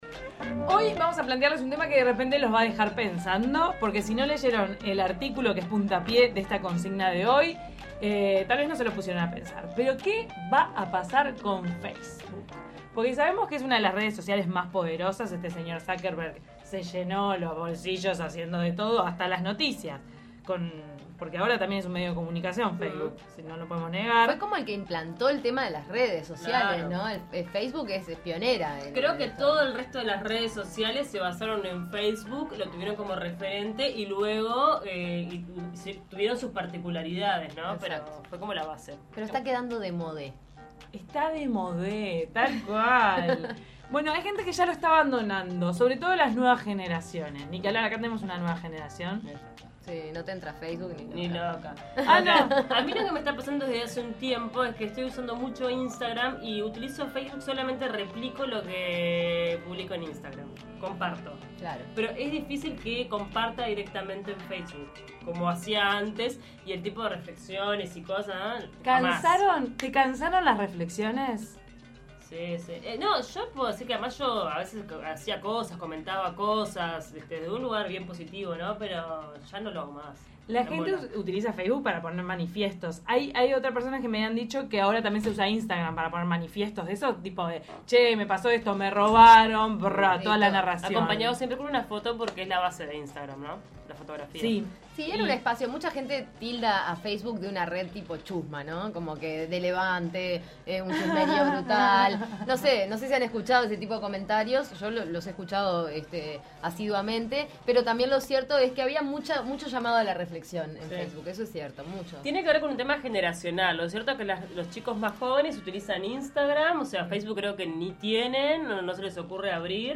A su vez, salieron a la calle a preguntarle a la gente si opina que Facebook continuará siendo utilizado o si cree que morirá.